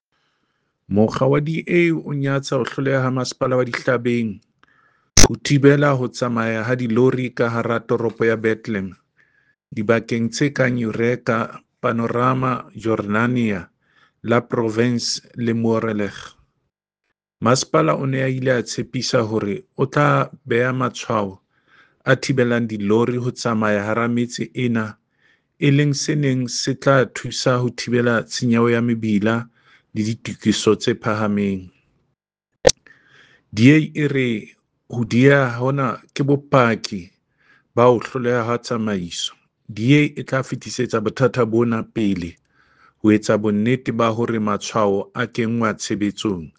Sesotho soundbite by David Masoeu MPL